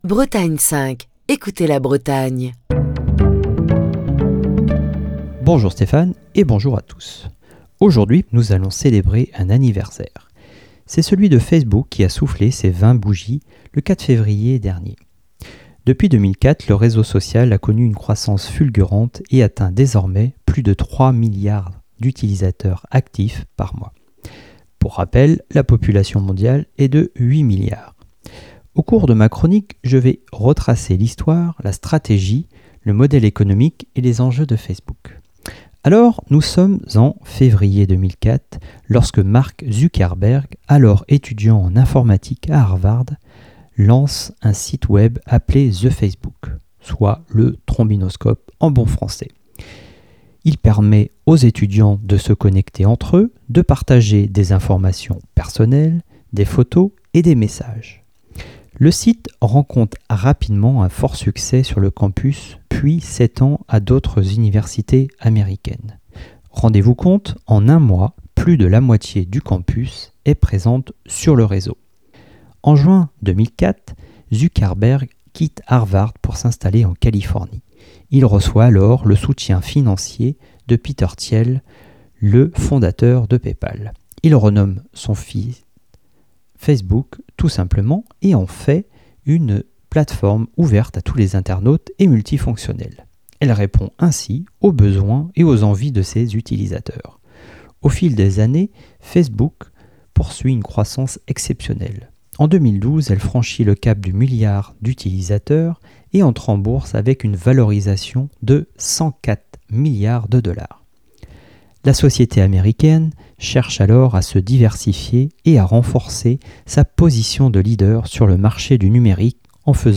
Chronique du 7 février 2024.